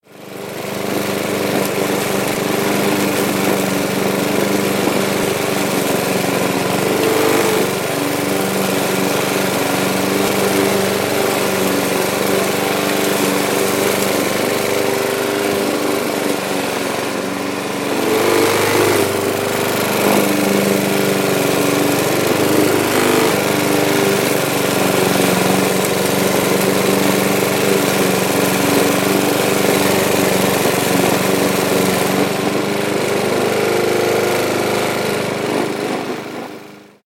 دانلود صدای ماشین چمن زن از ساعد نیوز با لینک مستقیم و کیفیت بالا
جلوه های صوتی
برچسب: دانلود آهنگ های افکت صوتی حمل و نقل